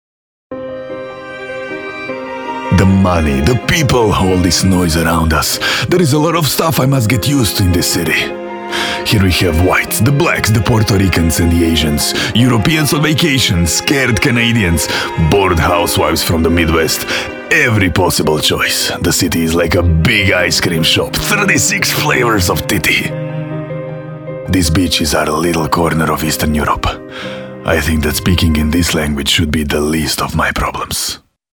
Native speaker Male 30-50 lat
Voice artist with a positive, pleasant voice and neutral accent.
Demo - język angielski (akcent wschodnioeuropejski)